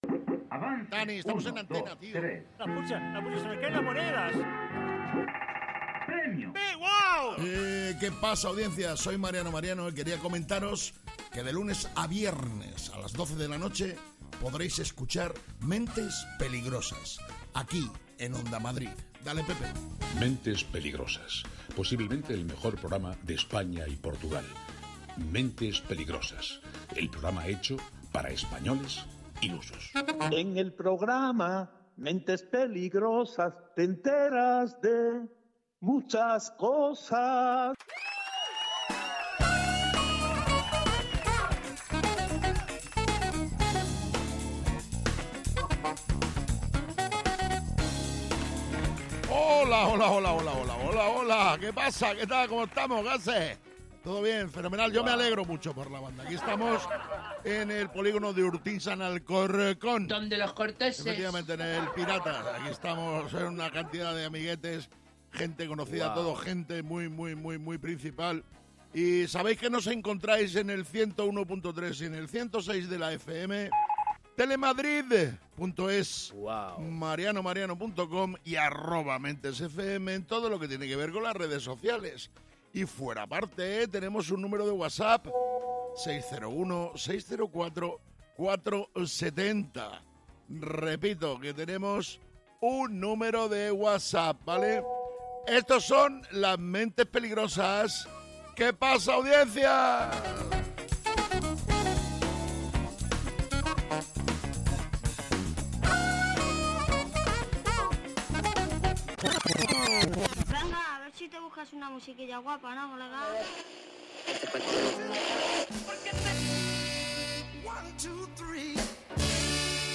Mentes Peligrosas de Mariano Mariano es un programa de radio en el que cada día se presenta una nueva aventura o no, depende siempre del estado anímico de los participantes en el mismo.